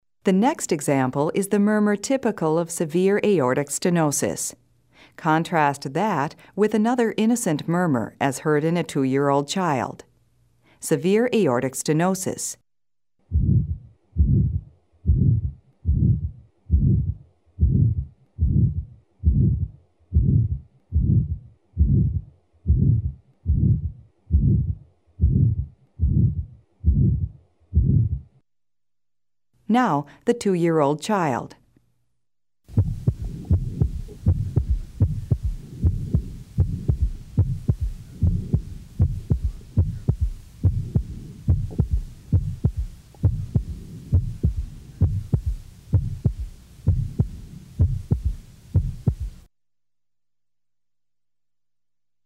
43 Murmur Typical of Severe Aortic S.mp3